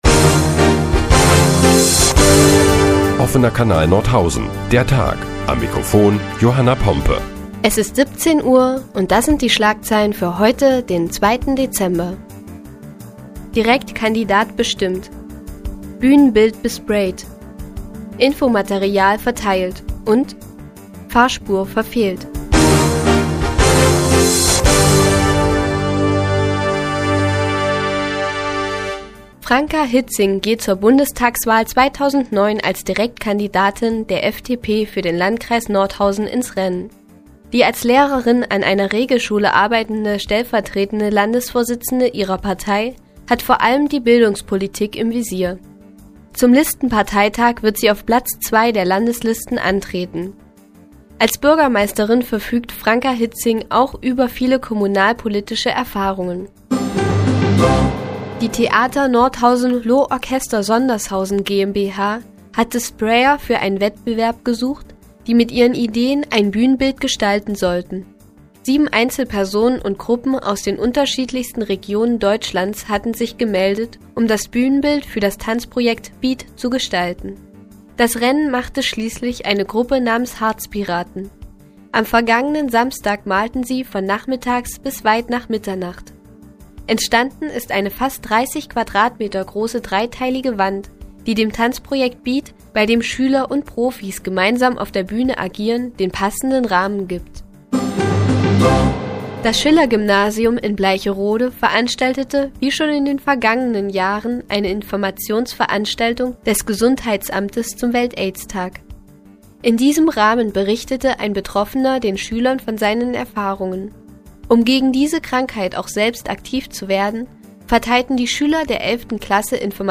Die tägliche Nachrichtensendung des OKN ist nun auch in der nnz zu hören. Heute geht es unter anderem um Sprayer im Theater und einen spektakulären Autounfall...